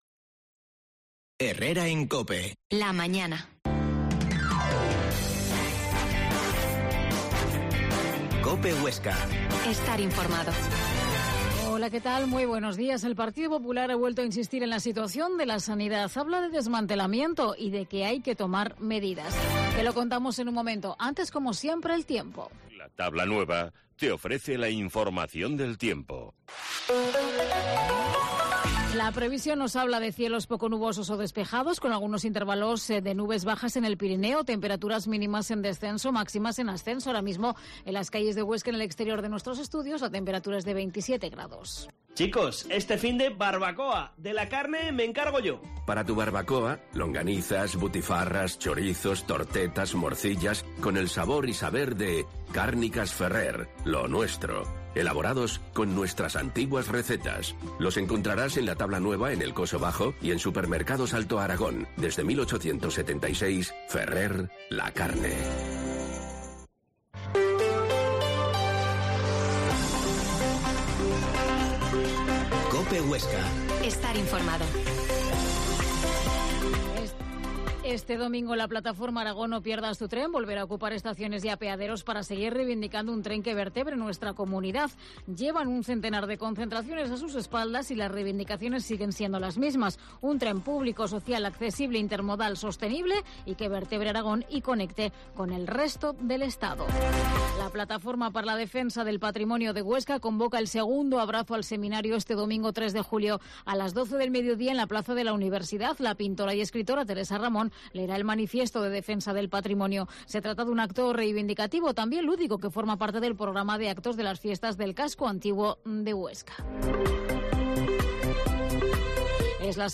Herrera en COPE Huesca 12.50h Entrevista a Gerardo Oliván, Presidente del PP de Huesca